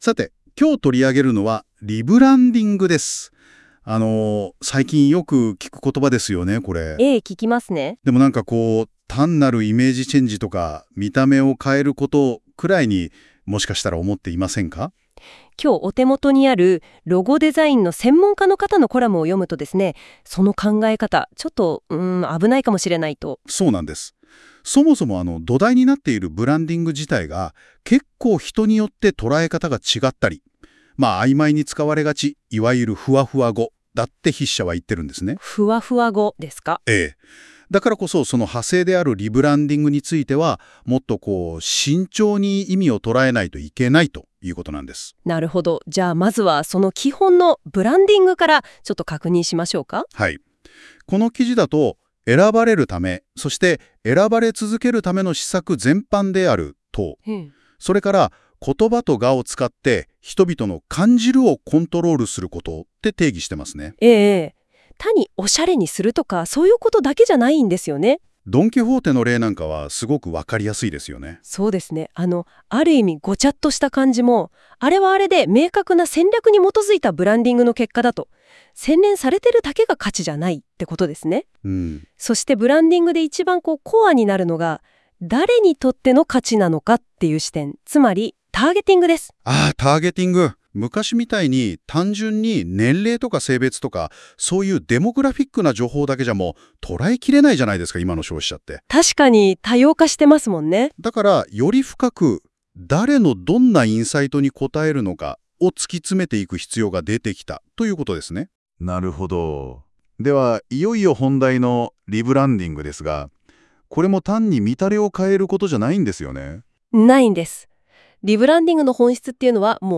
先日知人に教えてもらった生成AIが、「文章を解読してラジオ番組っぽく男女の会話にする」というものです。